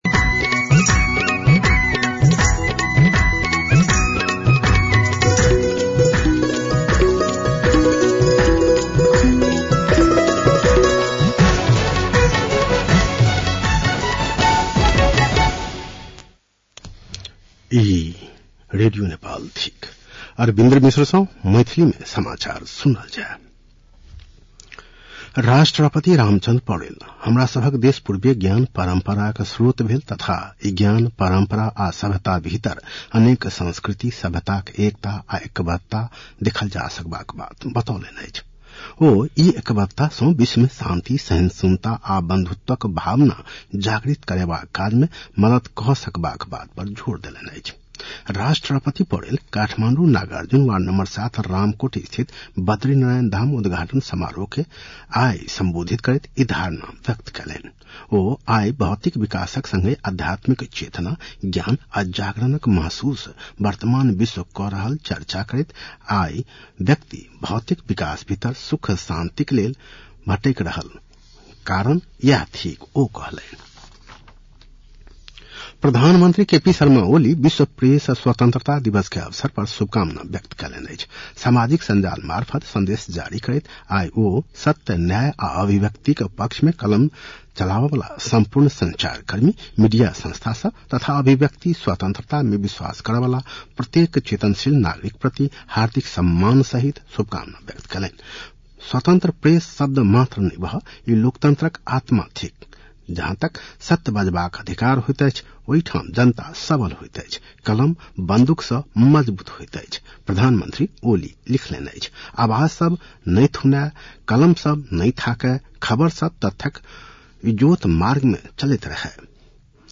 मैथिली भाषामा समाचार : २० वैशाख , २०८२